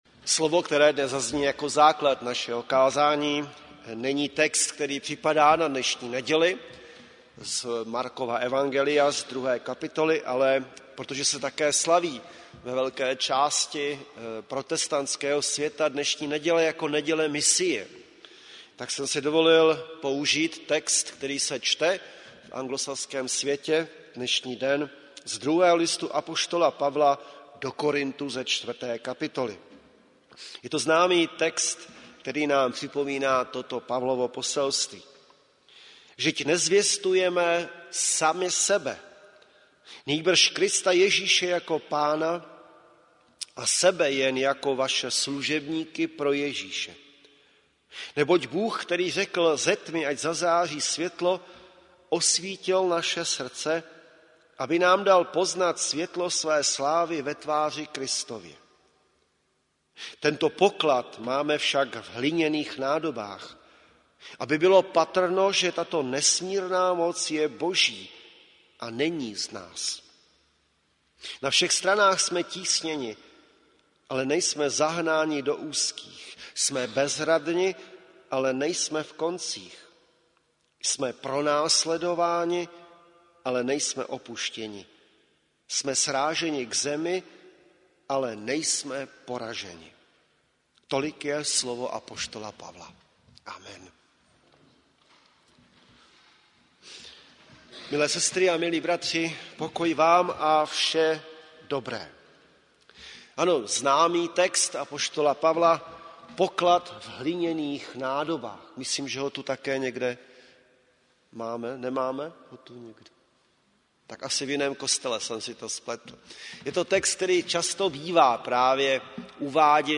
Záznam kázání z bohoslužeb konaných dne 10.6.2018 v evangelickém kostele Západního sboru ČCE v Plzni.